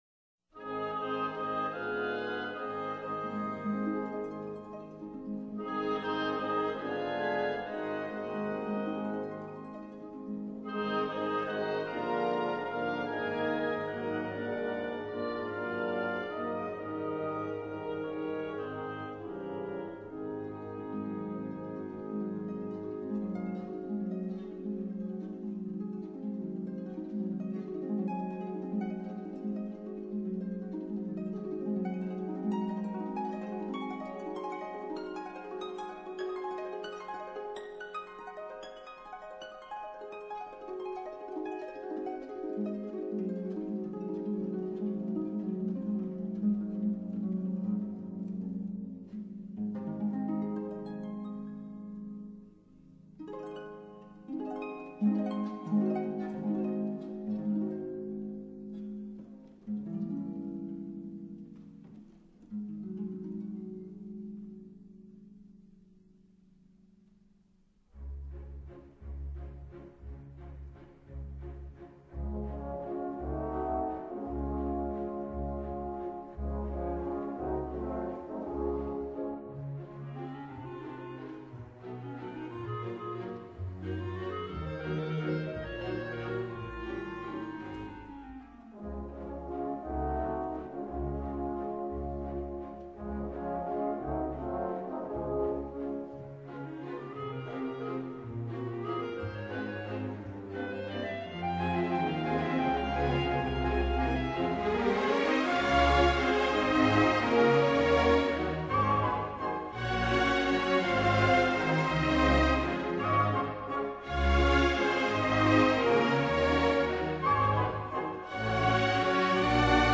موسیقی کلاسیک ، چایکوفسکی _ والس گل ها ، Tchaikovsky _ Waltz of the Flowers
موسیقی بی کلام